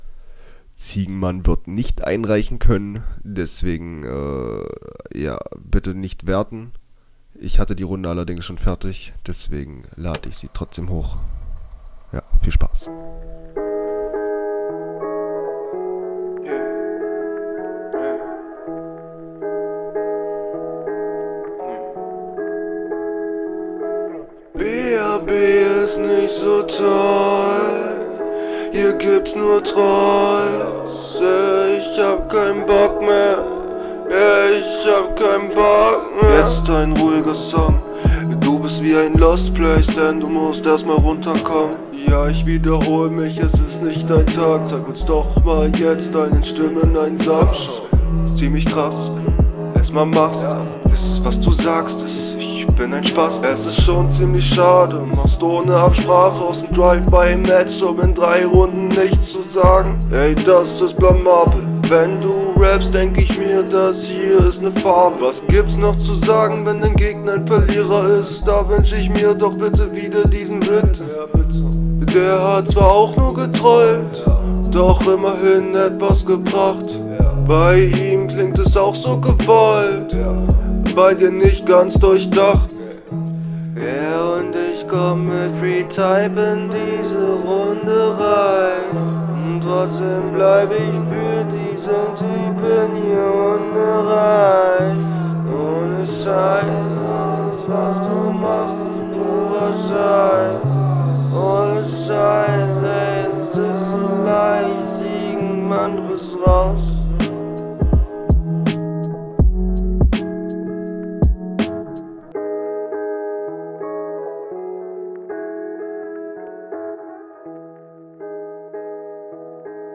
Der flow diesmal eigentlich ganz gut. Schlecht abgemischt meiner Meinung.